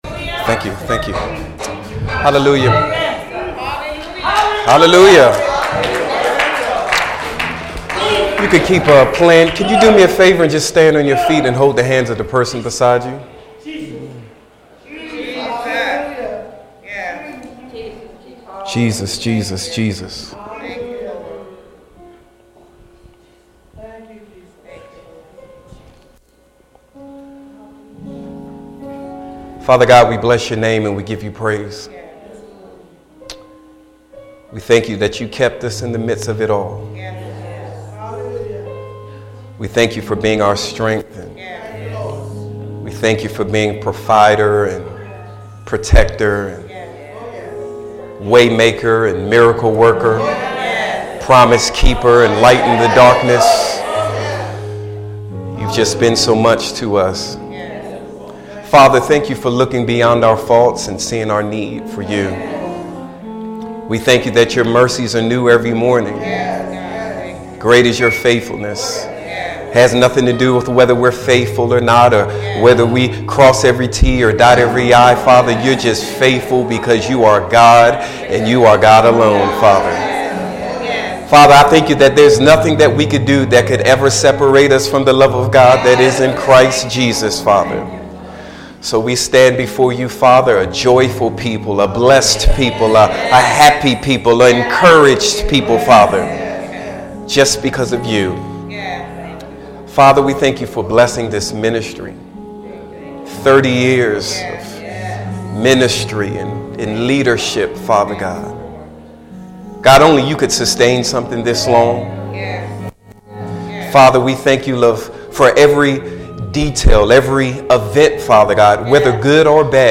Sermons - Spirit of Elijah Kingdom Church
Philippians 4:11-13 Share this sermon: